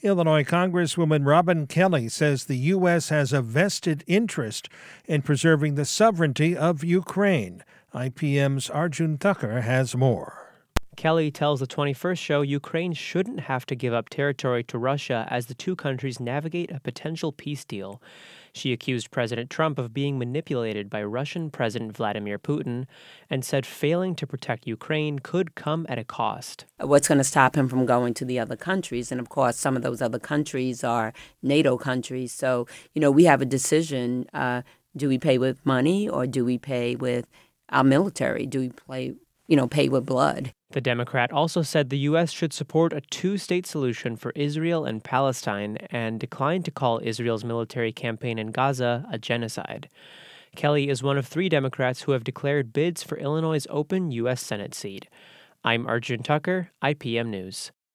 Congresswoman Robin Kelly talks with the 21st Show about her bid for U.S. Senate
Interview Highlights